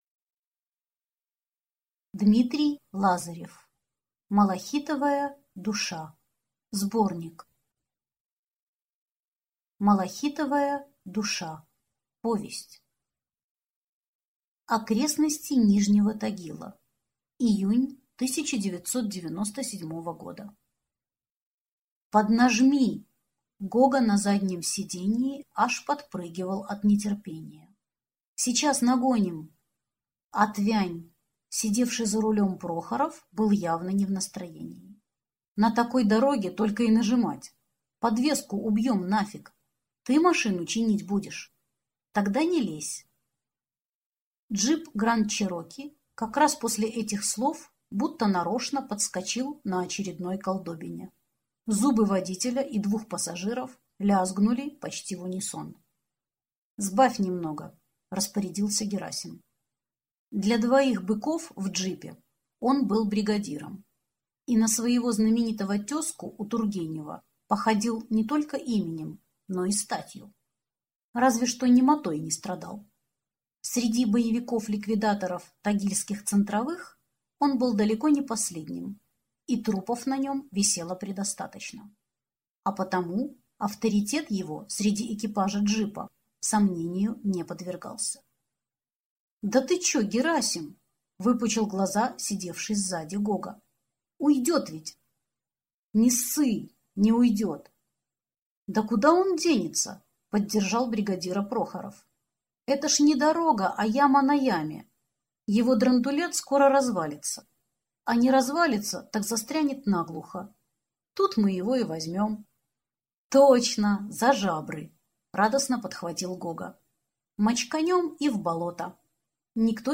Аудиокнига Малахитовая душа (сборник) | Библиотека аудиокниг